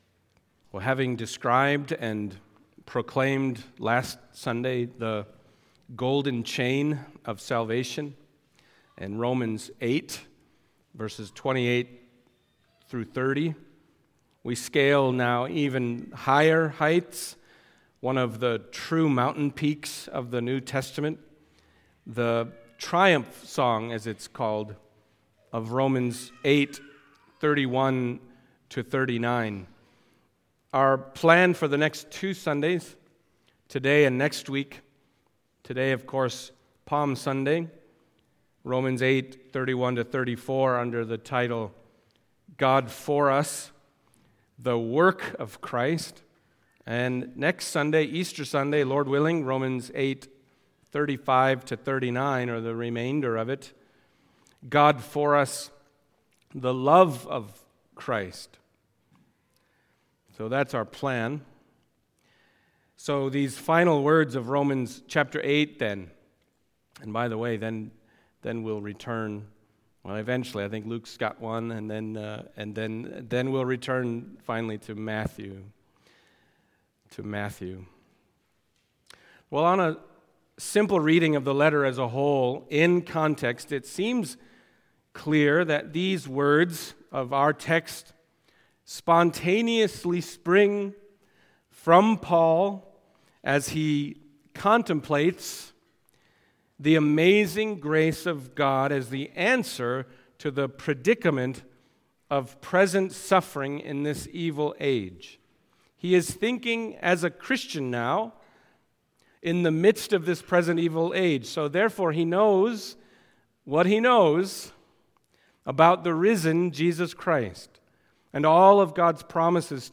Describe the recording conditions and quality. Other Passage: Romans 8:31-34 Service Type: Sunday Morning Romans 8:31-34 « The Golden Chain God For Us